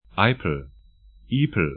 Eipel aipl Ipel’ i:pl sk Fluss / stream 47°48'N, 18°52'E